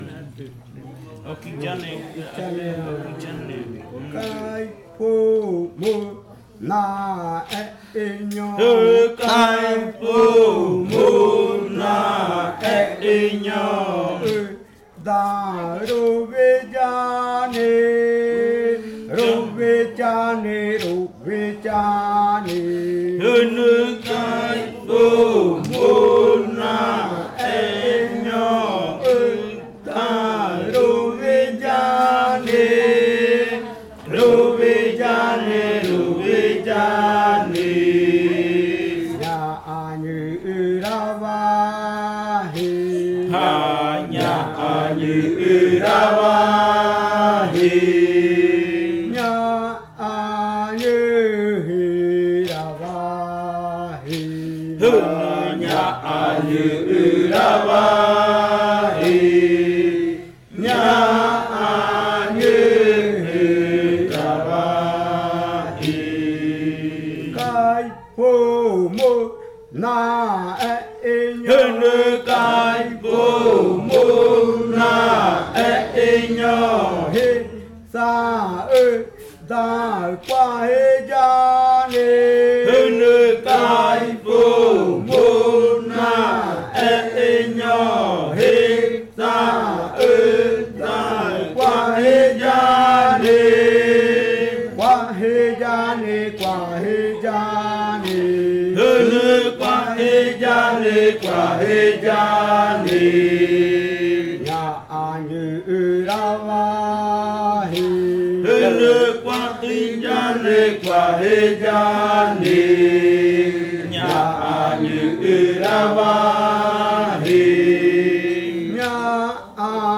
Canto de saltar de la variante jimokɨ
Leticia, Amazonas
con el grupo de cantores sentado en Nokaido.
with the group of singers seated in Nokaido.